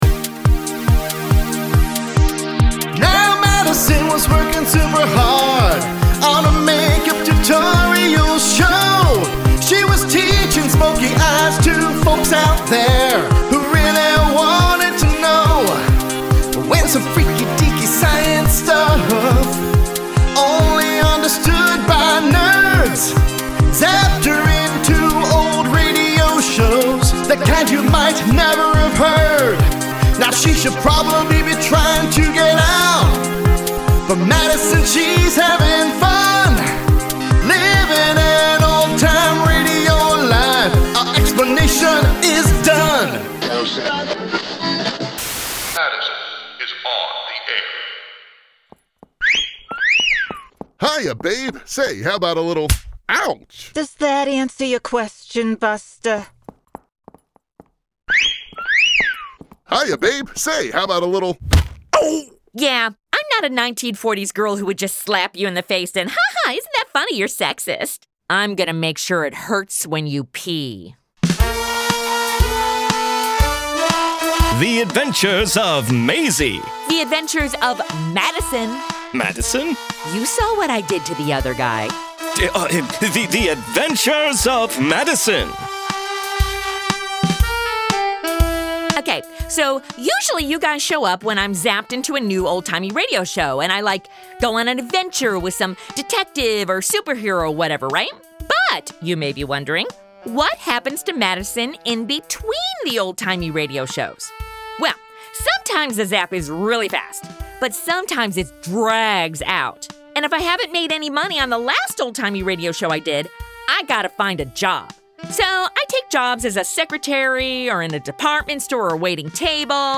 Follow Madison as she looks for work in between being zapped into her next big old time radio adventure.